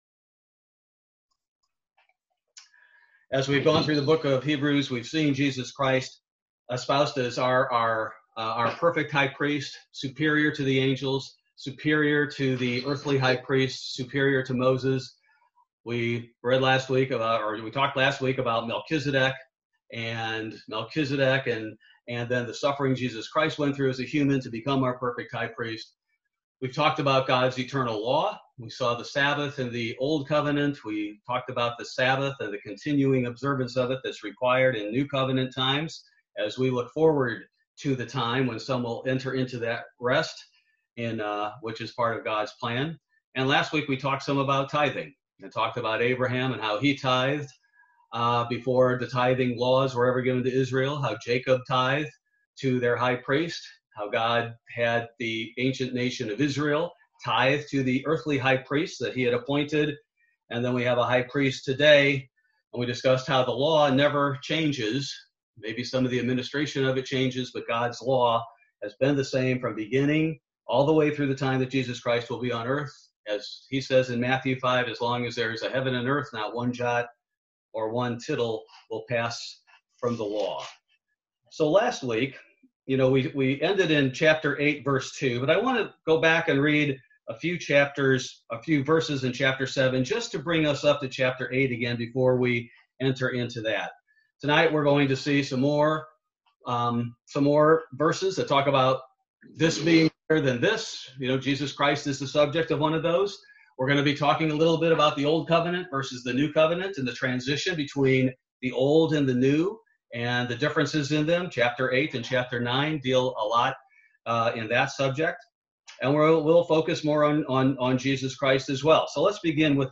Bible Study: January 6, 2021